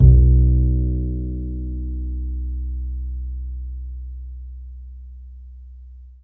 DBL BASS C#2.wav